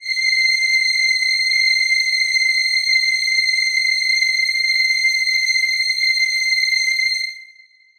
Choir Piano
C7.wav